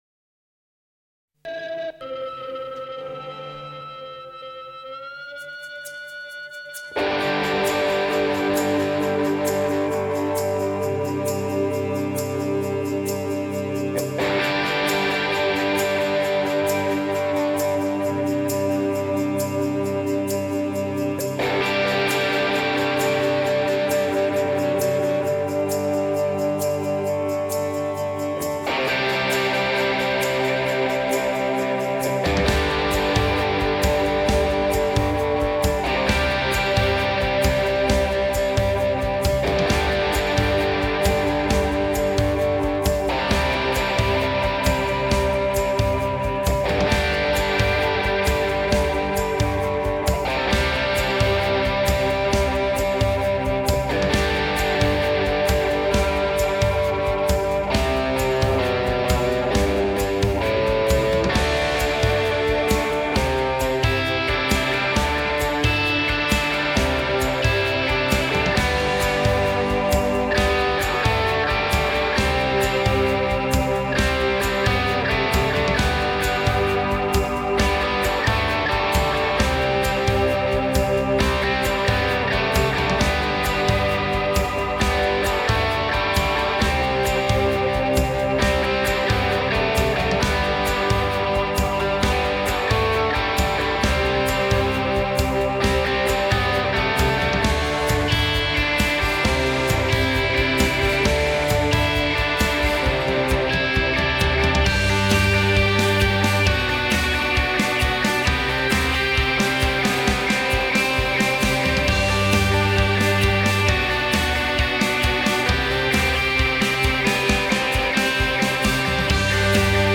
BPM : 132
Tuning : E
Without vocals